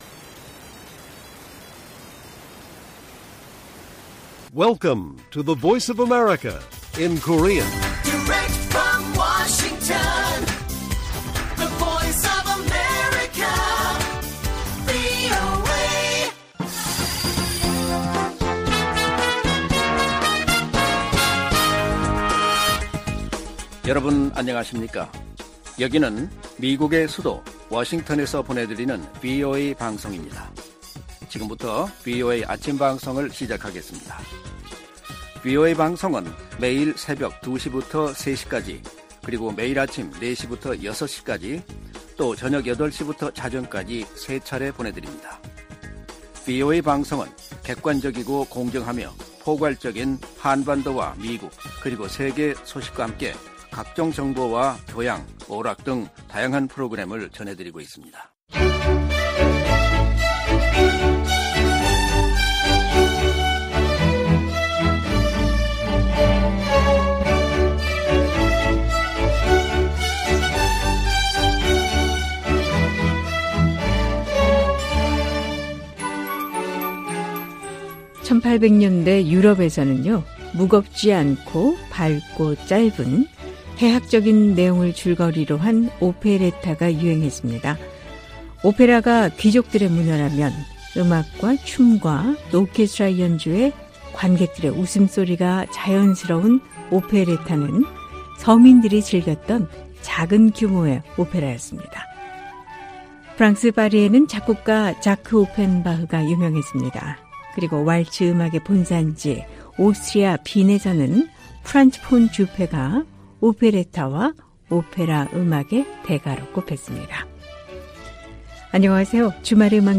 VOA 한국어 방송의 일요일 오전 프로그램 1부입니다. 한반도 시간 오전 4:00 부터 5:00 까지 방송됩니다.